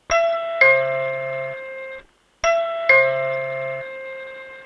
9 belklanken met regelbaar volume
-- M-E-6x0 belklank-keuzemogelijkheden (klik op bel):